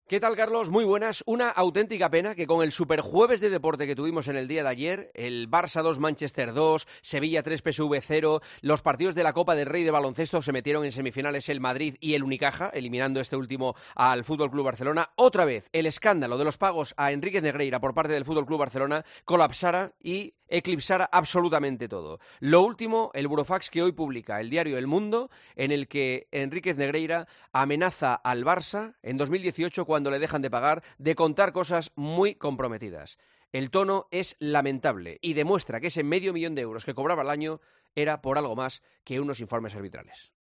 El comentario de Juanma Castaño
El presentador de 'El Partidazo de COPE' analiza la actualidad deportiva en 'Herrera en COPE'